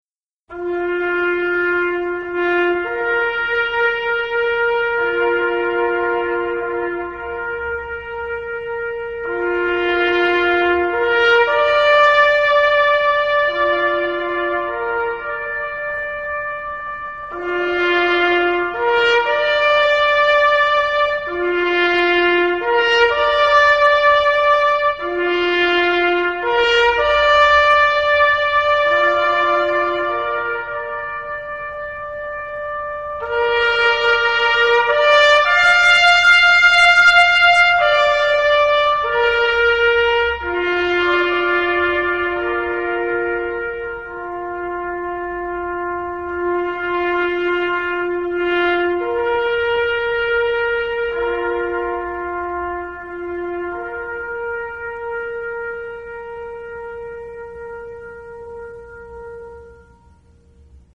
Interview took place in 1987.